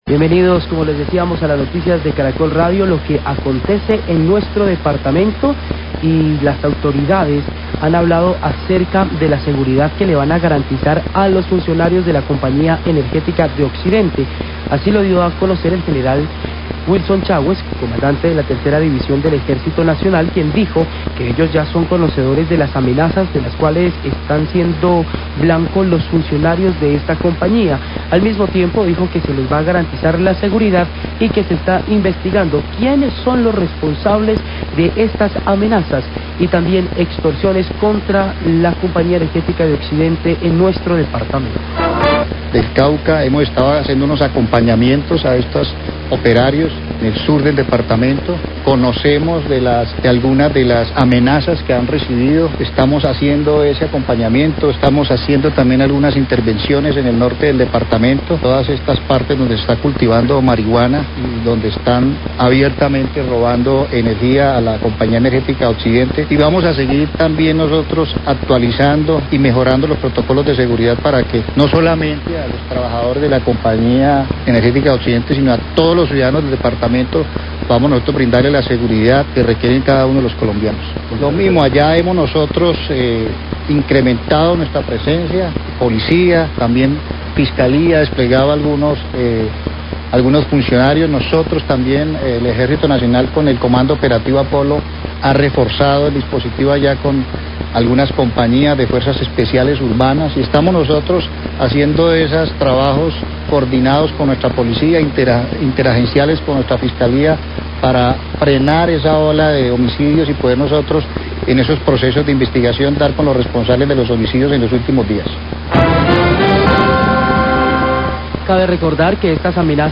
Radio
El Ejército Nacional ofrecerá garantías de seguridad a los operarios de la Compañía Energética, se investiga quienes son los responsables de estas amenazas y extorsiones. Declaraciones del General Wilson Chawes, Comandante de la Tercera División del Ejército Nacional.